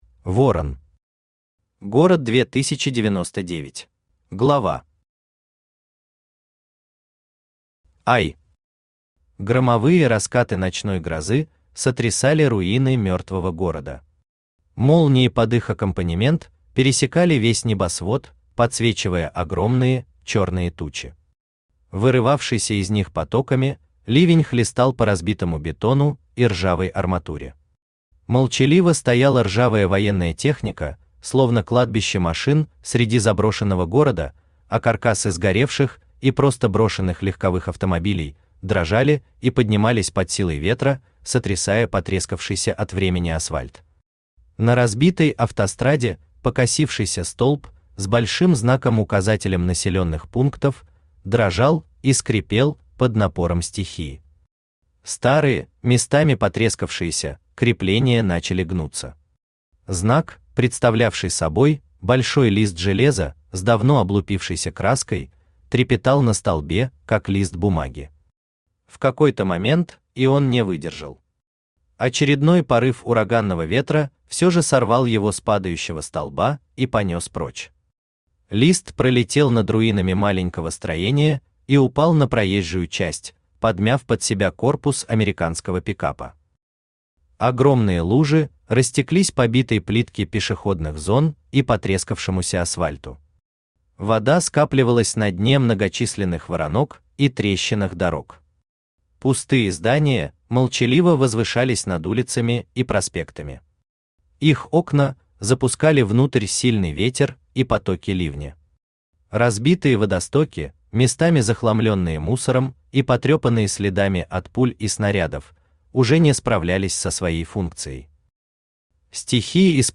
Аудиокнига Город 2099 | Библиотека аудиокниг
Aудиокнига Город 2099 Автор Ворон Читает аудиокнигу Авточтец ЛитРес. Прослушать и бесплатно скачать фрагмент аудиокниги